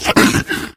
flesh_pain_1.ogg